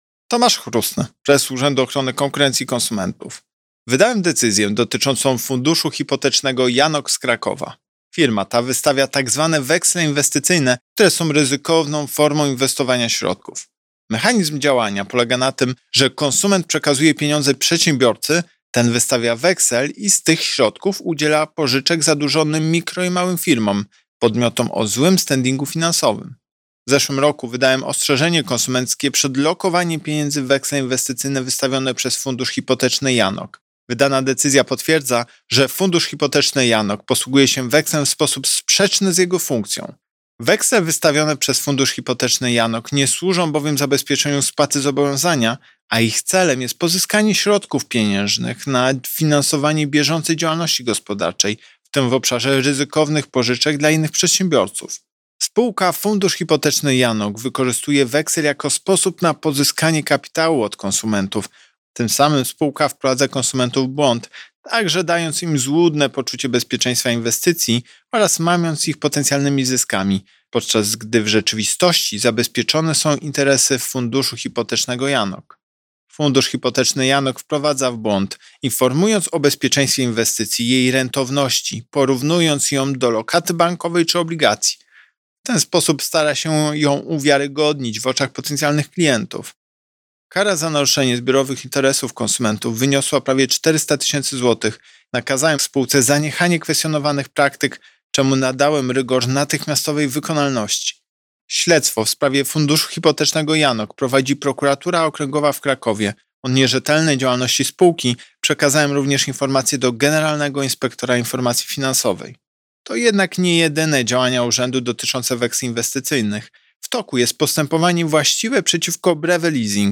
Wypowiedź Prezesa UOKiK Tomasza Chróstnego z 5 lipca 2021 r..mp3